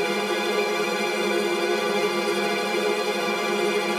Index of /musicradar/gangster-sting-samples/Chord Loops
GS_TremString-Fdim.wav